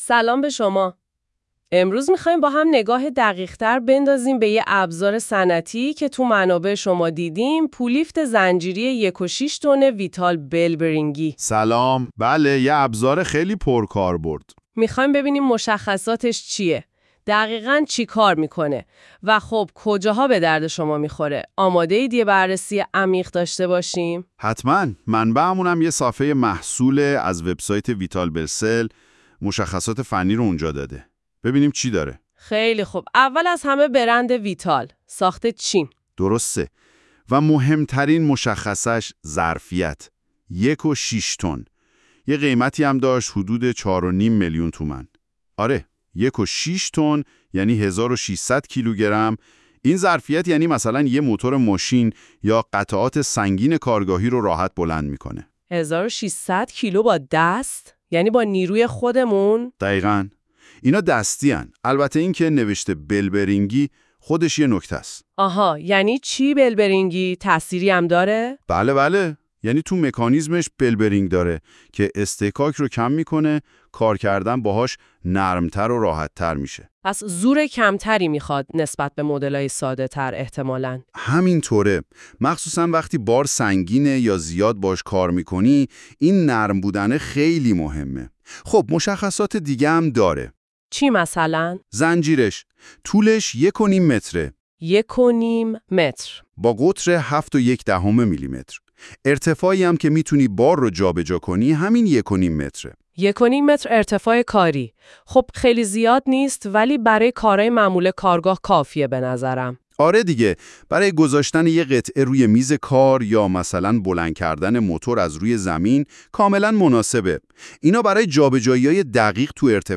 معرفی-پولیفت-زنجیری-1_6-تن-ویتال.wav